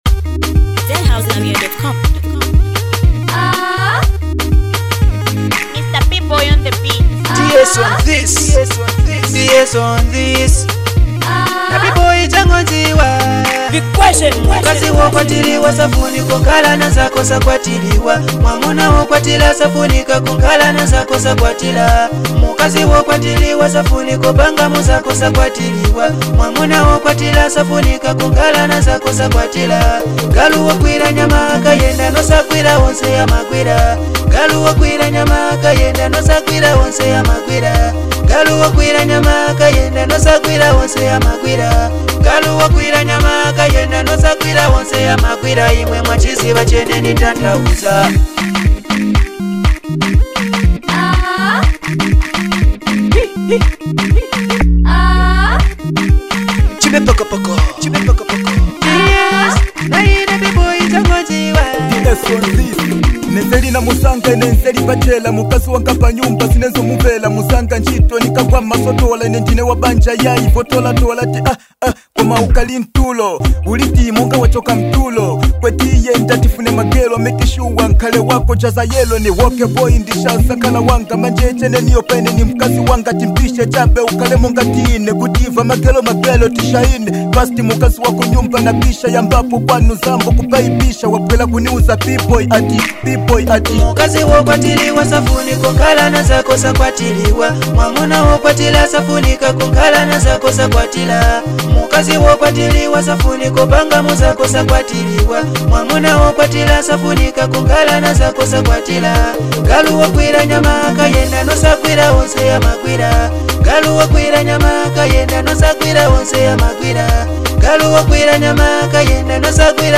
a street anthem
With catchy beats and real-life storytelling